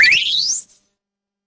Power-Up
An ascending power-up jingle with shimmering synth tones signaling an upgrade
power-up.mp3